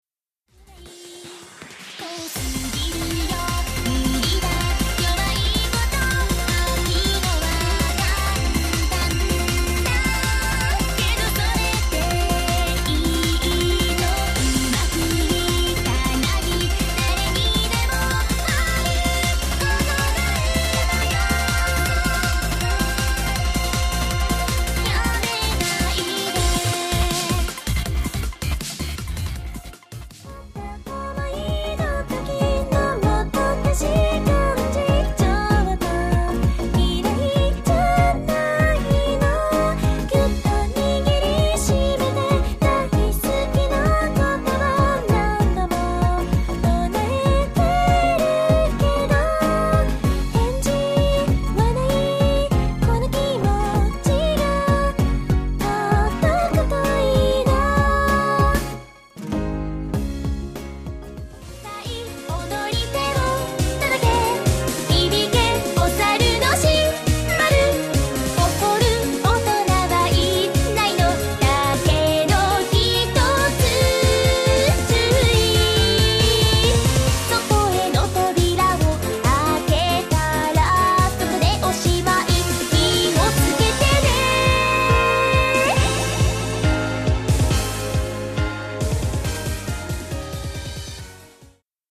★ 全体クロスフェードデモ
Vocal&Words